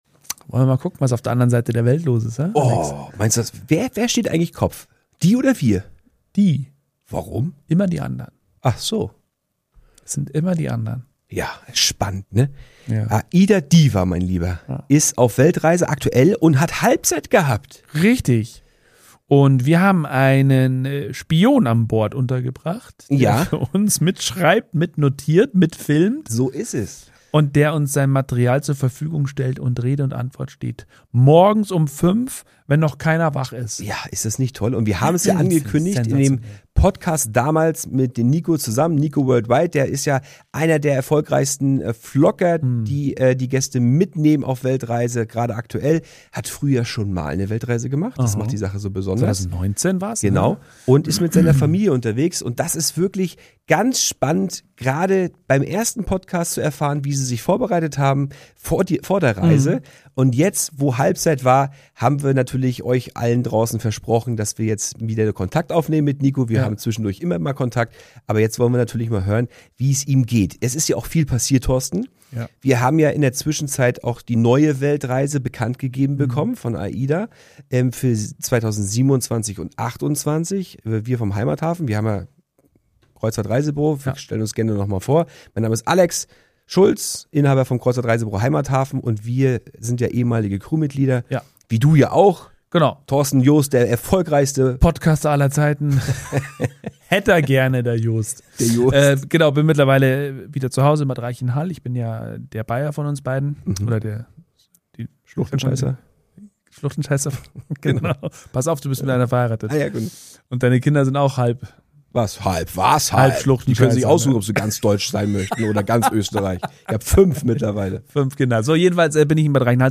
Beschreibung vor 2 Monaten Halbzeit auf der AIDA Weltreise – Zeit für ein ehrliches Zwischenfazit direkt von Bord.
Ein authentisches Gespräch mit echten Eindrücken vom Leben auf See.